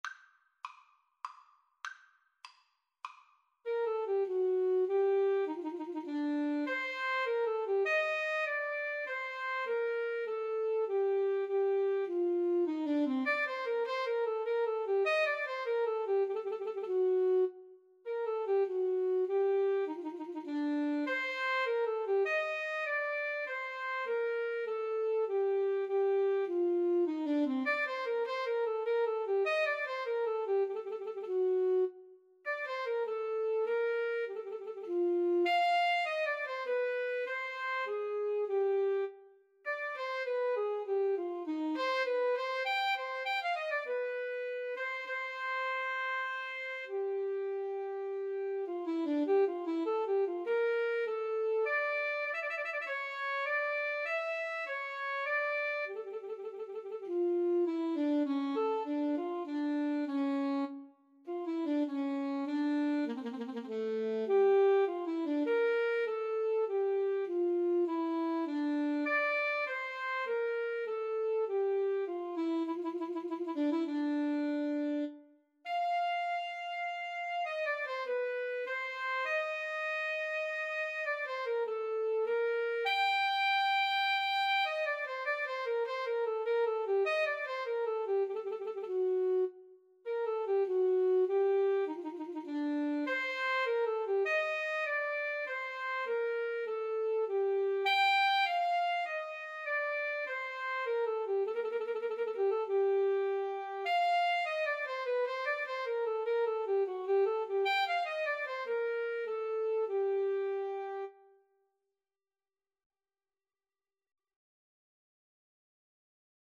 3/4 (View more 3/4 Music)
Tempo di menuetto